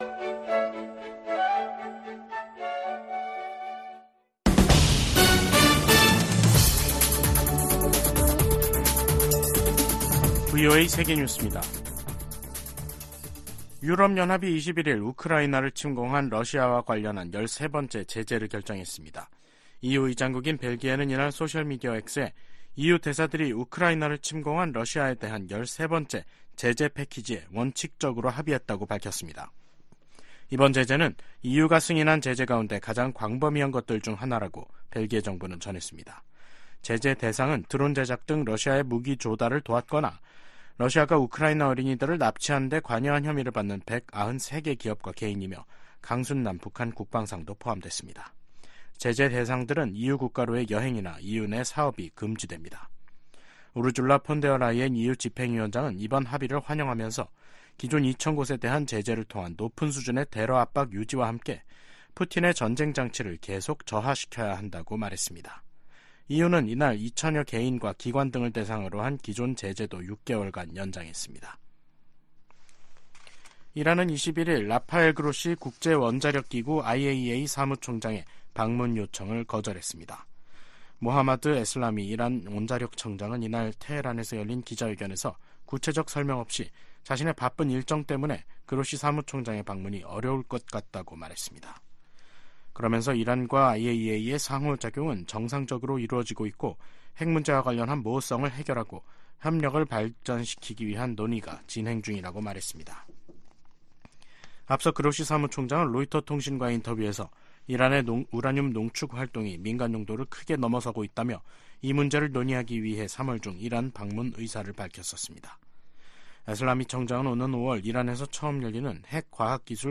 VOA 한국어 간판 뉴스 프로그램 '뉴스 투데이', 2024년 2월 21일 3부 방송입니다. 러시아가 우크라이나 공격에 추가로 북한 미사일을 사용할 것으로 예상한다고 백악관이 밝혔습니다. 미국 정부는 북일 정상회담 가능성에 역내 안정에 기여한다면 환영할 일이라고 밝혔습니다.